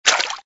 TT_splash2.ogg